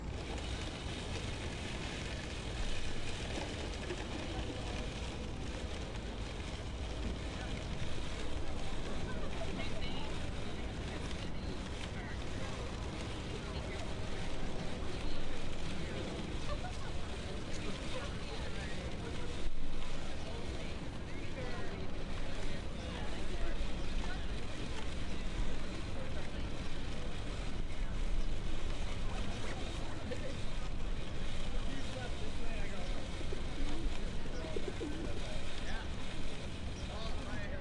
City Park Portlan Oregon Airplane (Noise Growingin BG)
描述：This is a recording of a city park in downtown Portland, Oregon. There are a lot of hard/cement surfaces. (A lot of red bricks) An airplane engine creeps in throughout the track.
标签： Ambiance Park City
声道立体声